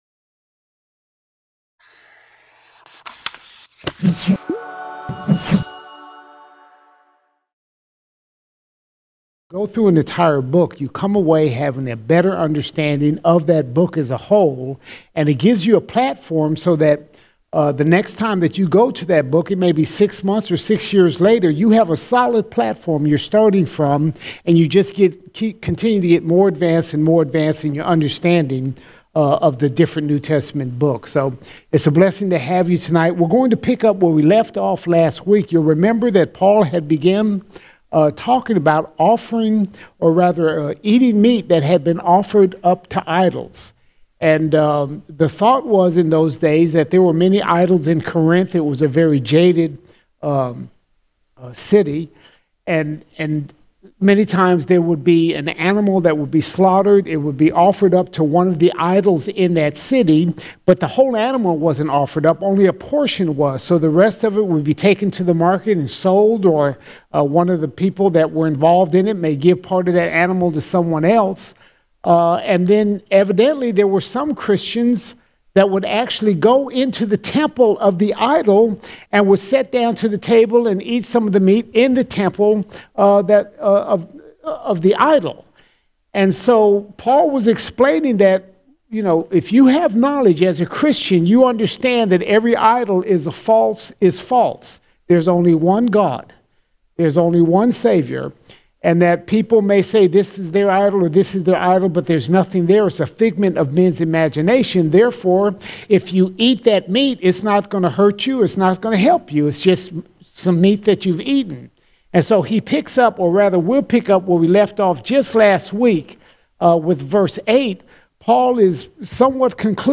17 December 2020 Series: 1 Corinthians All Sermons 1 Corinthians 8:10 to 9:22 1 Corinthians 8:10 to 9:22 Paul explains supporting the ministers laboring in the Gospel.